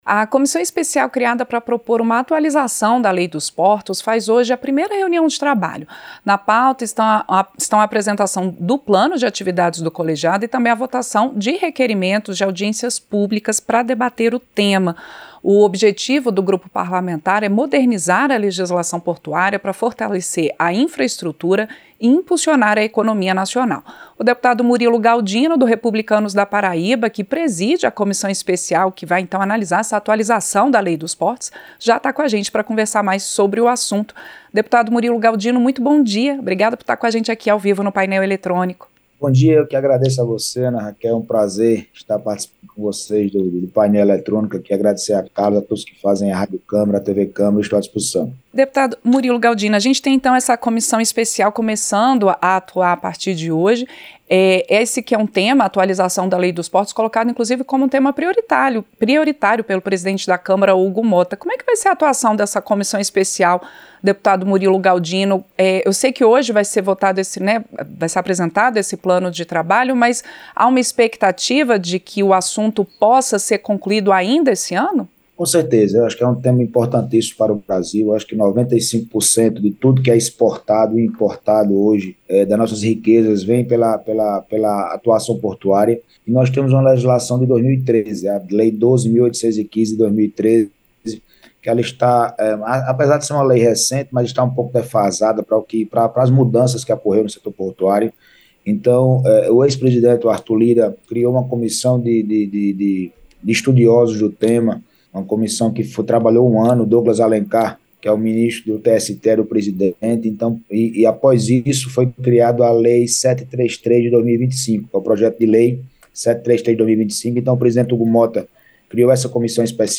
• Entrevista - Dep. Murilo Galdino (Republicanos-PB)
Programa ao vivo com reportagens, entrevistas sobre temas relacionados à Câmara dos Deputados, e o que vai ser destaque durante a semana.